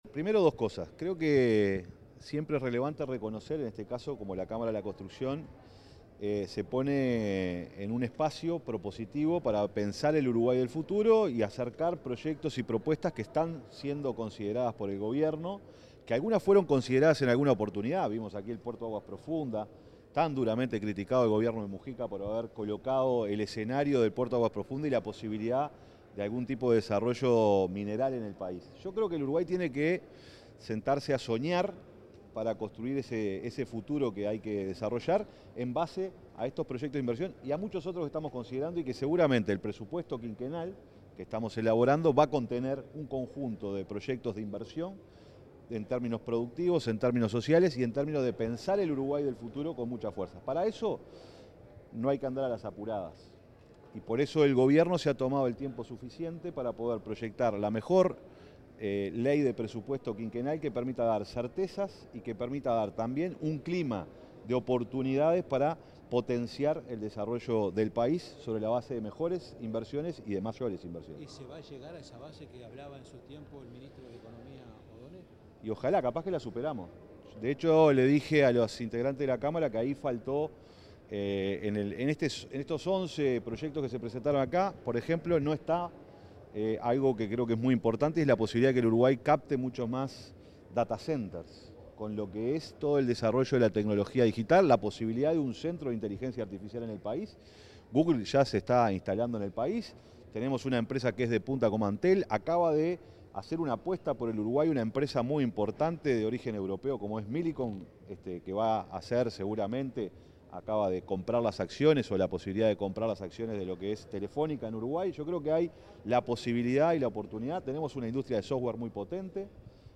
Declaraciones a la prensa del secretario de Presidencia, Alejandro Sánchez
Declaraciones a la prensa del secretario de Presidencia, Alejandro Sánchez 05/06/2025 Compartir Facebook X Copiar enlace WhatsApp LinkedIn El secretario de la Presidencia de la República, Alejandro Sánchez, dialogó con los medios de prensa tras el lanzamiento del proyecto La Infraestructura en Desarrollo, por la Cámara de la Construcción del Uruguay.